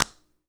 Clap18.wav